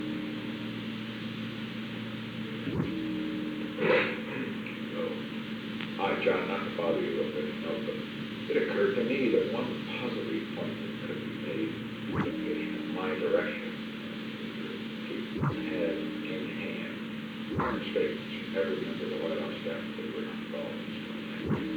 Secret White House Tapes
Location: Executive Office Building
The President talked with John D. Ehrlichman.